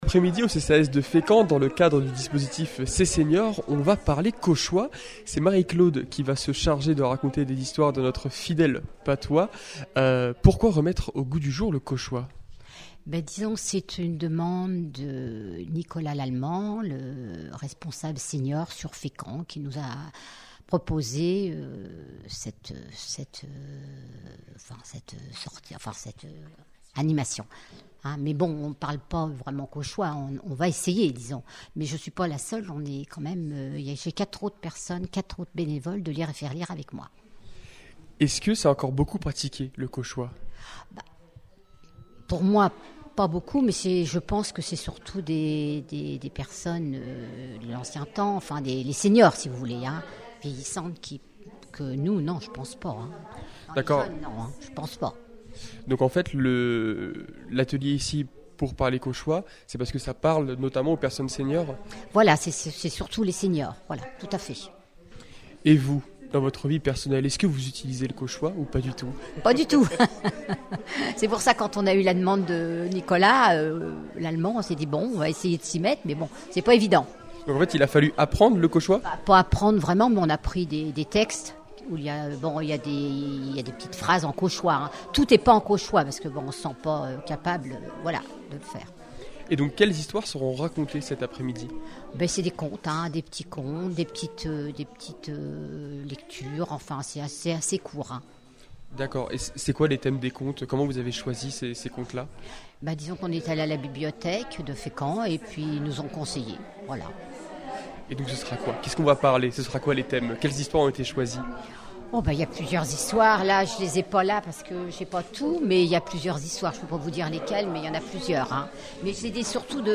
Les interviews Radar Actu Interview fécamp podcast